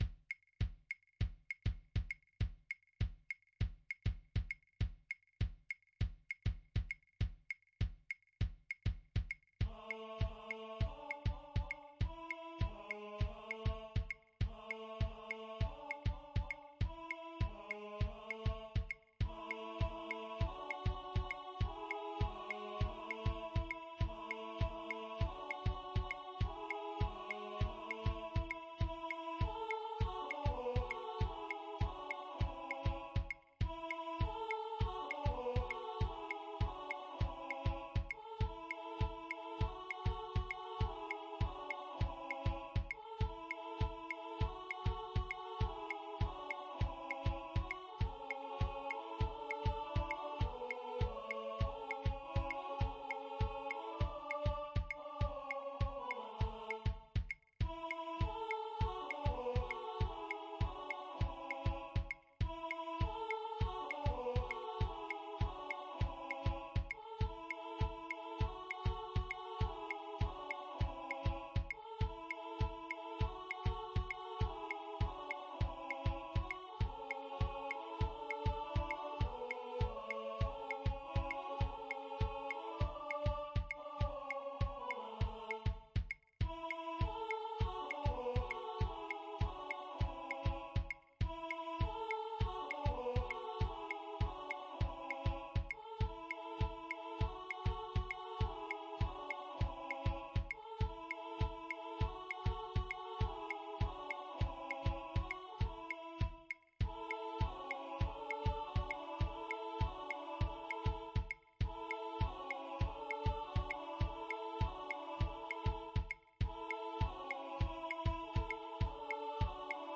pdmx-multi-instrument-synthesized